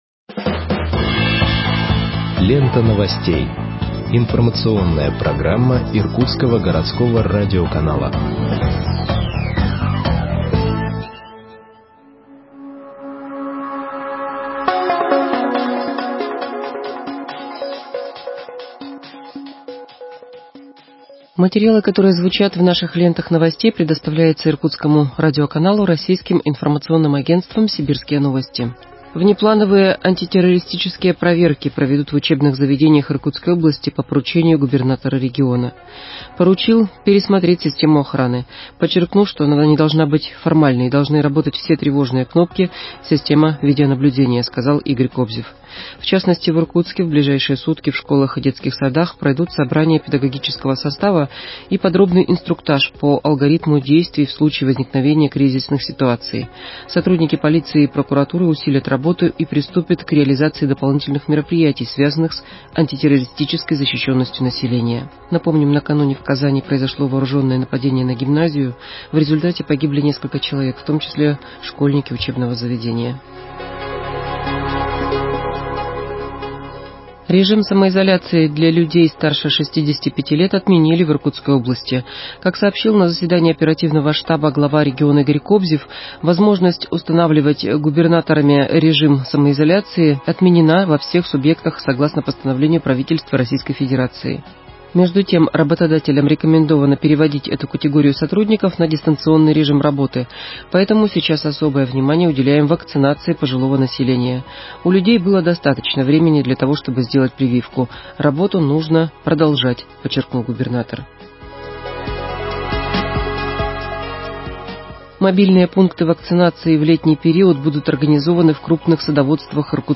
Выпуск новостей в подкастах газеты Иркутск от 12.05.2021 № 2